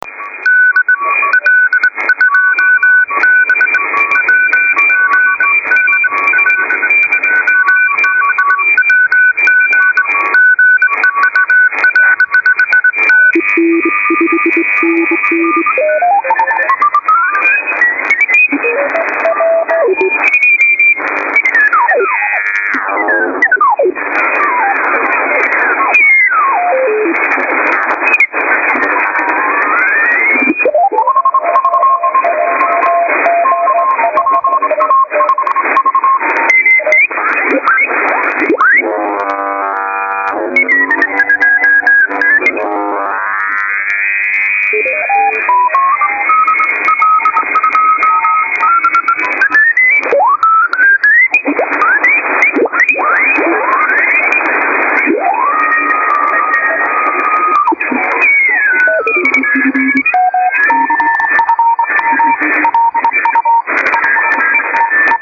Connected a 40m antenna, headphones and a 12v battery supply.
I used HDSR and what an improvement. Listen here to a sample of the audio with DSP